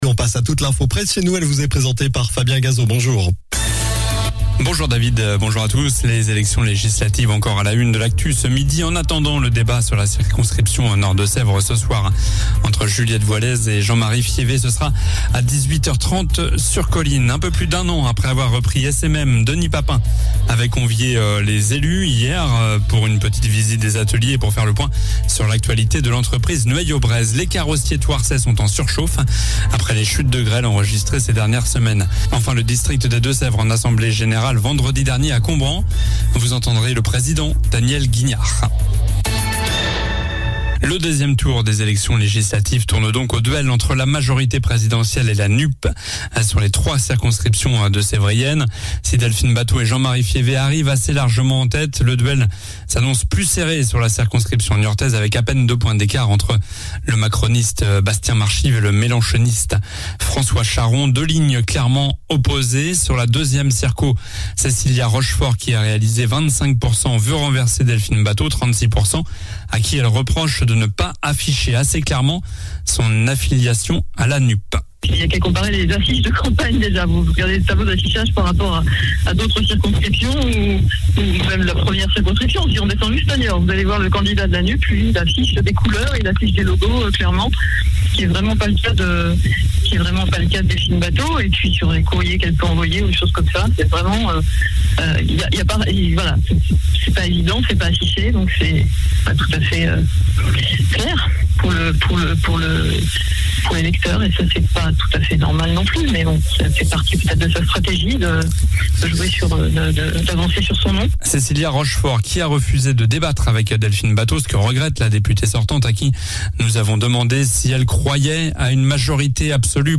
COLLINES LA RADIO : Réécoutez les flash infos et les différentes chroniques de votre radio⬦
Journal du mardi 14 juin (midi)